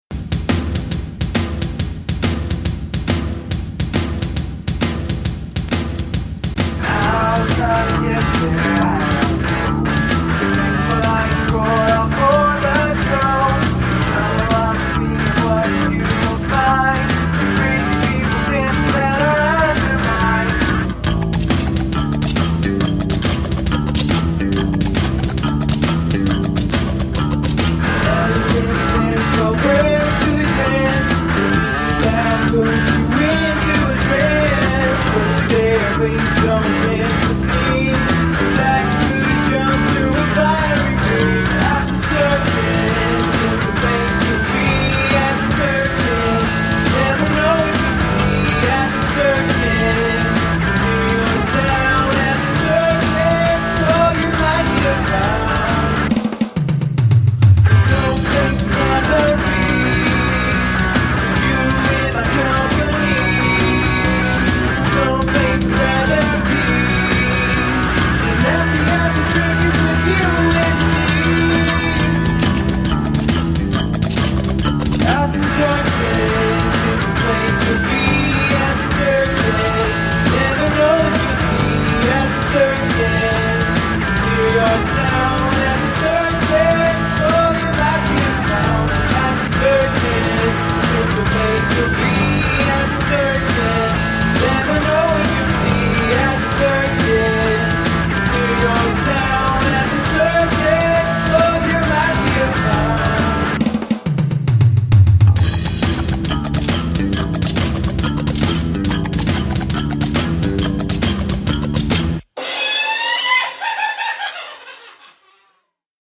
Use something from your garbage bin as an instrument
Sloppy feel and the vocals are buried.